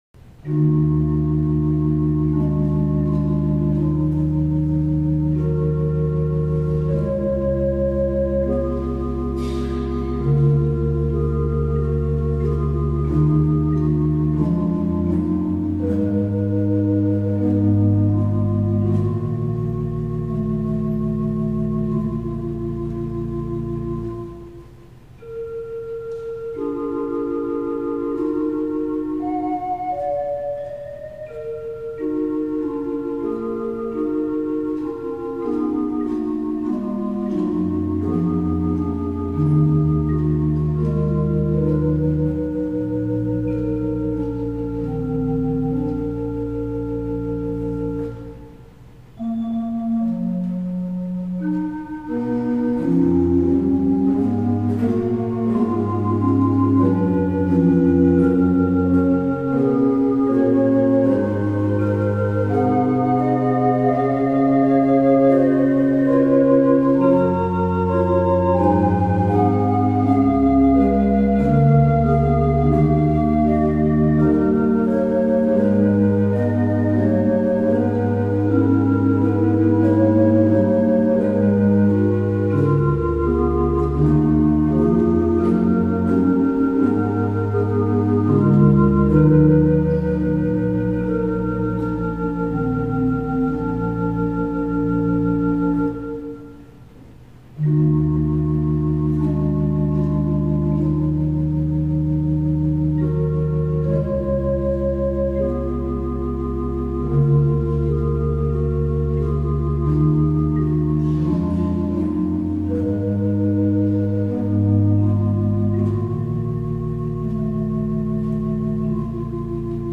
Konzertmitschnitt 2008